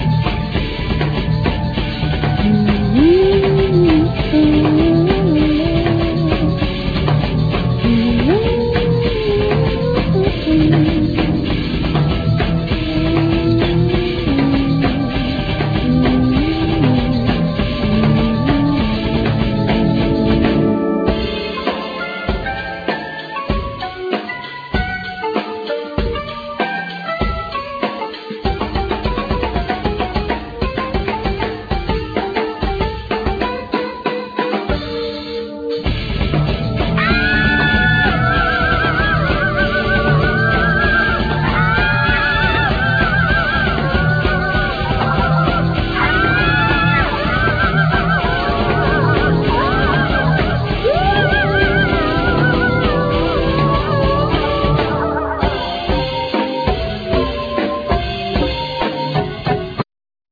Drums,Bass,Keyboards,Vocal abuse
Guitar
The gospel voice
Marimba
Tuba